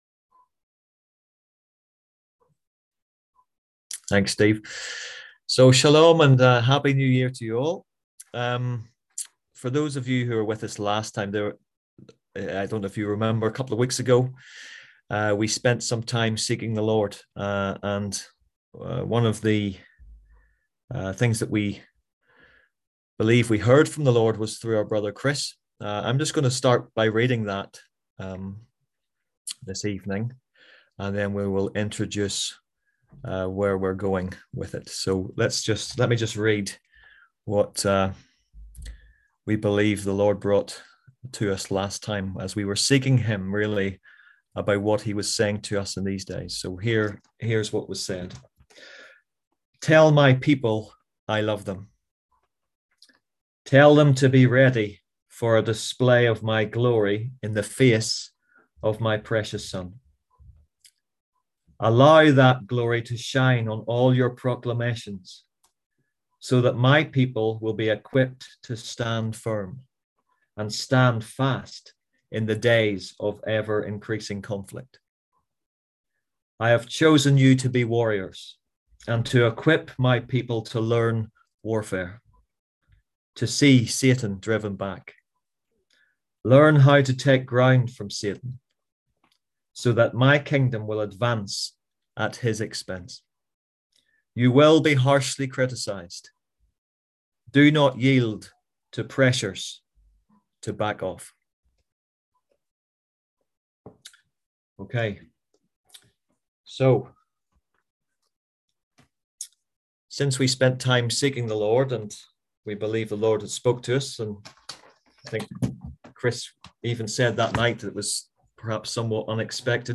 On January 3rd at 7pm – 8:30pm on ZOOM ASK A QUESTION – Our lively discussion forum. Tonight’s topic: A mind for war?